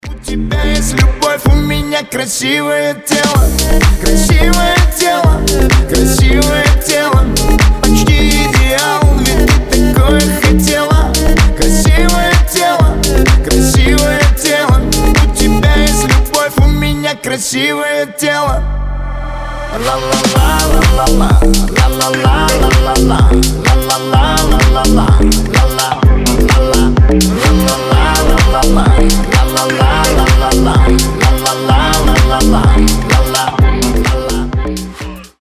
поп , танцевальные